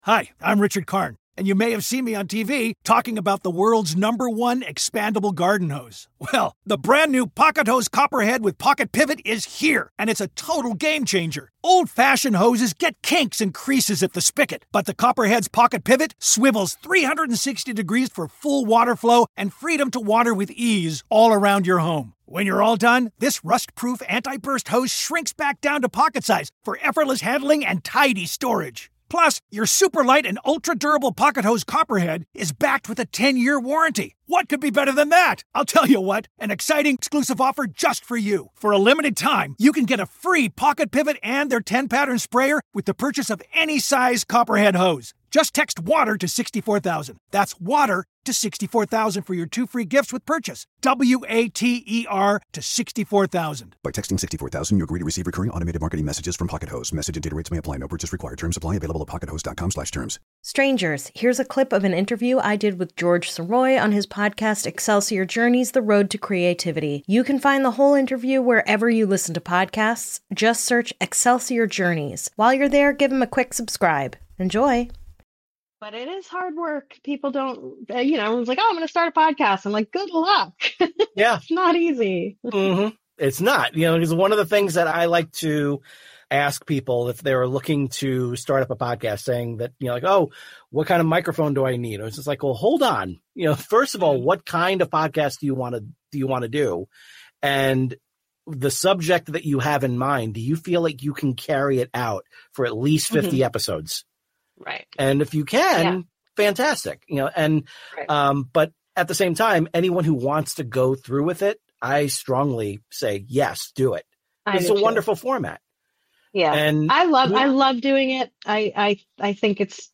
sits down with Daisy Eagan, the host of the hit podcast Strange and Unexplained and the youngest female to win a Tony Award. The two discuss Daisy's journey in creativity from performing to writing to getting behind the microphone, and both get to share their own insight on constantly moving forward with ADHD, self-doubt, and other ailments doing their part to impede in their progress. And Daisy shares some information on her latest venture, a podcast network called Grab Bag Collab.